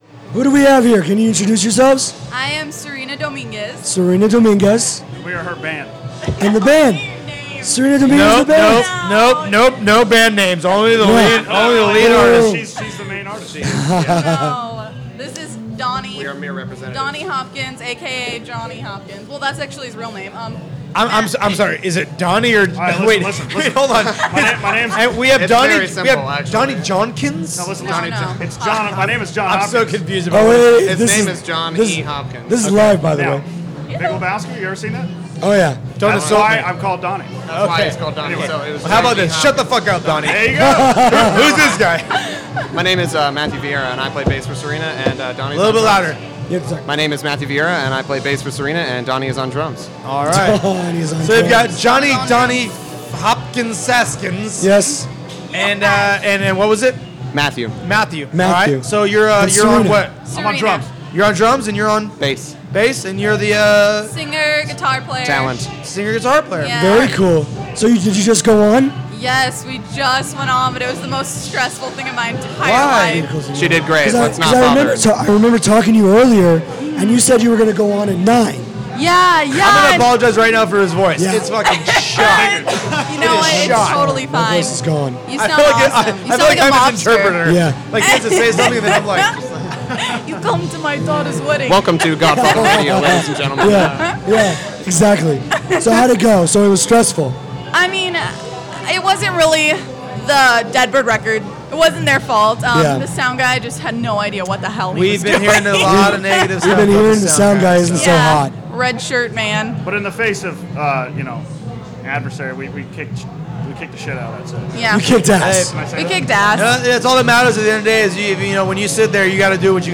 To play individual band interviews, just choose from the following: